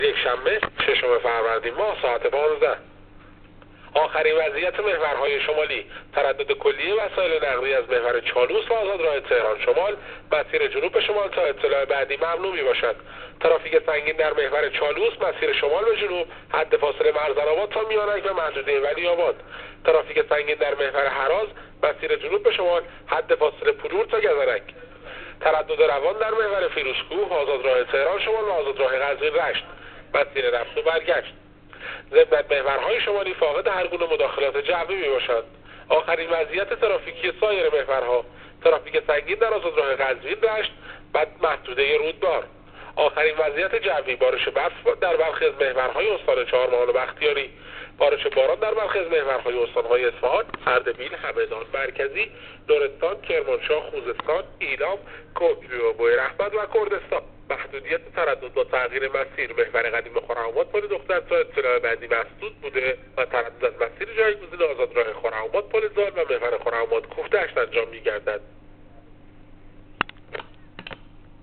گزارش رادیو اینترنتی از آخرین وضعیت ترافیکی جاده‌ها تا ساعت ۱۵ ششم فروردین ماه؛